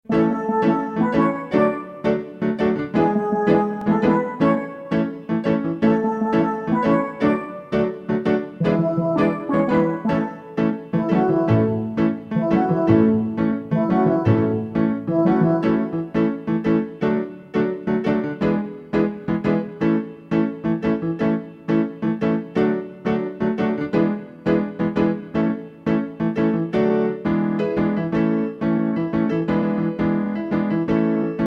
Listen to the instrumental backup track.